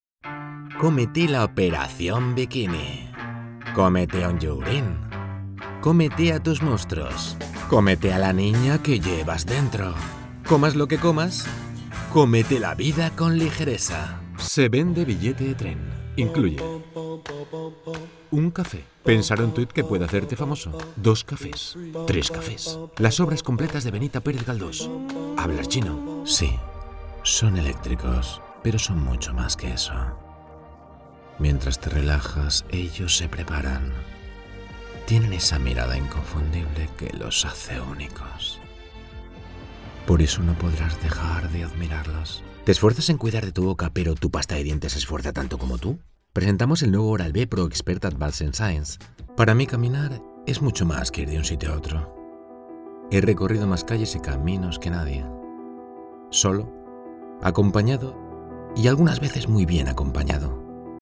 Démo commerciale
Voz neutral, emotiva, energética y divertida
Profesional Studio at home